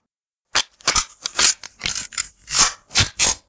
Fire Weapons Sound Effects – Assault-rifle-reload – Free Music Download For Creators
Fire_Weapons_Sound_Effects_-_assault-rifle-reload.mp3